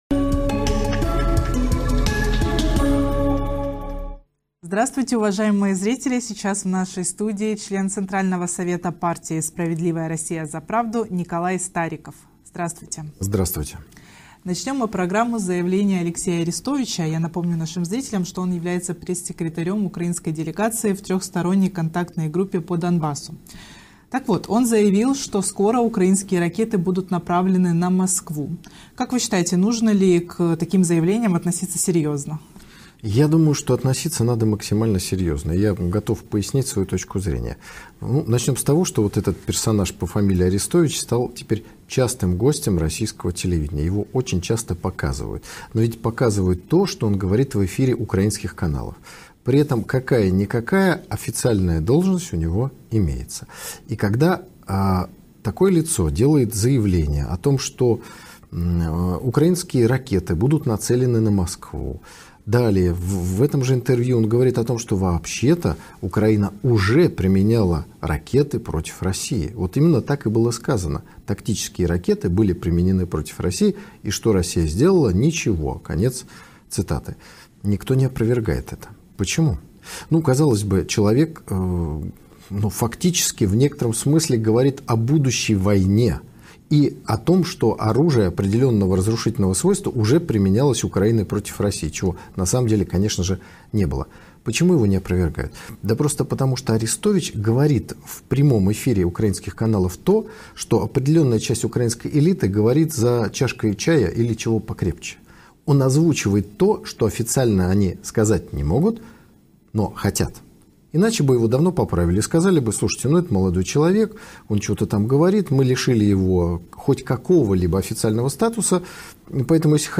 Итоги конкурса В новом интервью ресурсу «Украина.РУ» поговорили об агрессивных намерениях Украины и НАТО, а также о том, чем чревато вступление Украины в Североатлантический альянс. Также коснулись целого ряда важных тем последней недели, включая выступление Президента России на Валдае.